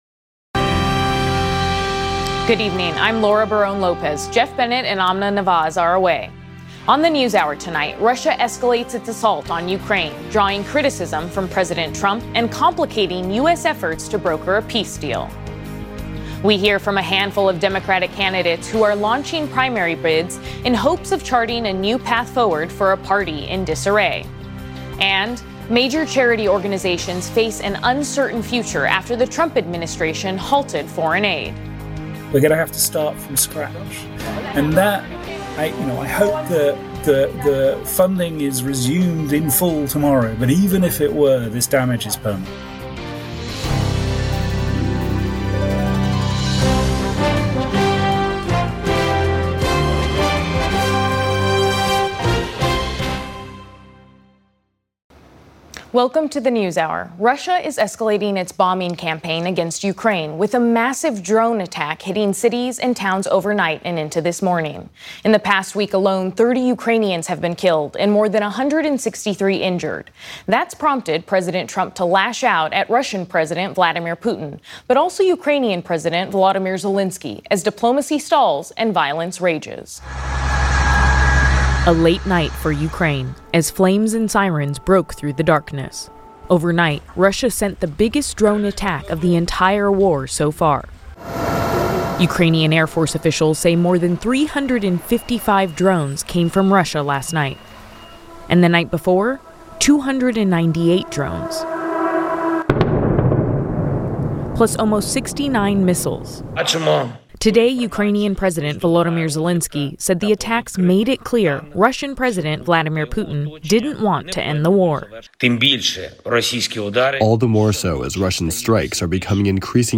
Monday on the News Hour, Russia escalates its assault on Ukraine, drawing criticism from President Trump and complicating U.S. efforts to broker a peace deal. We hear from Democratic candidates who are launching primary bids in hopes of charting a new path forward for a party in disarray. Plus, major charity organizations face an uncertain future after the Trump administration halted foreign aid.